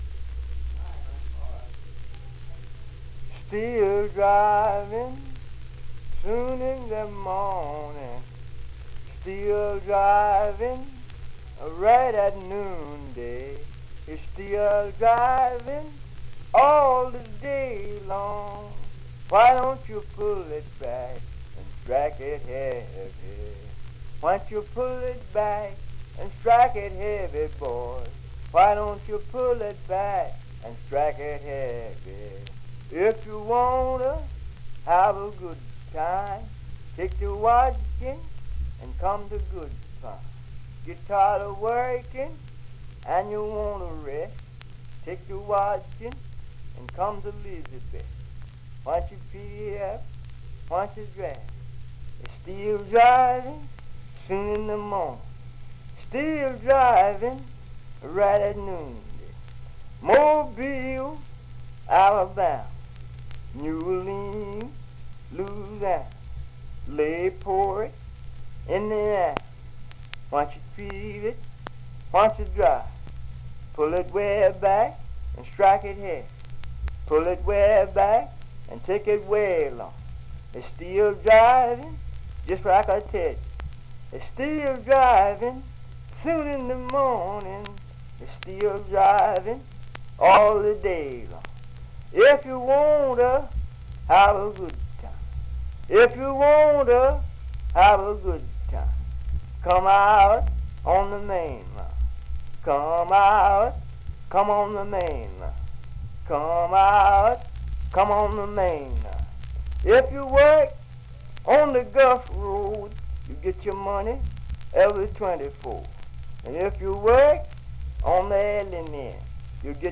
"Steel-Driving Song" sung by a worker on the railroad recorded during the Depression Era